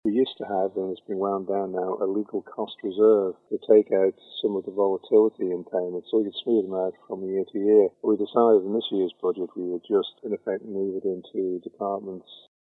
That's the Treasury Minister - who says the measure is one of many to help stabilise government finances.